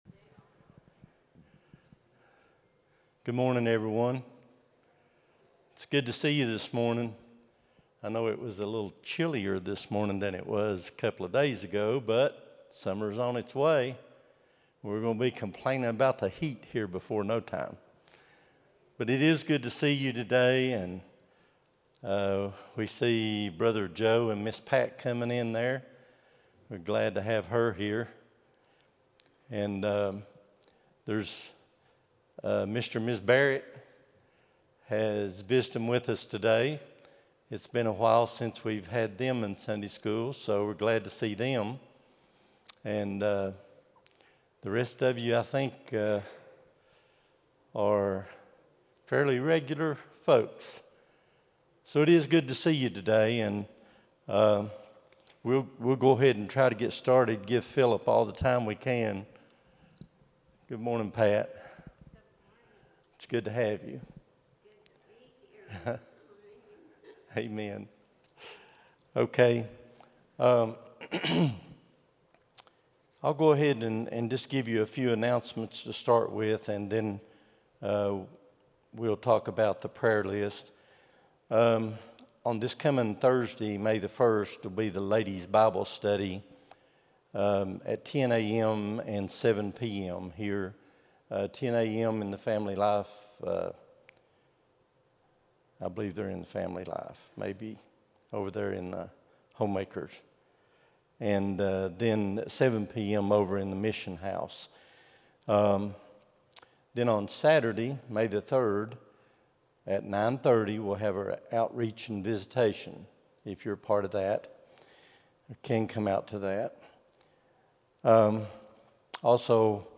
04-27-25 Sunday School | Buffalo Ridge Baptist Church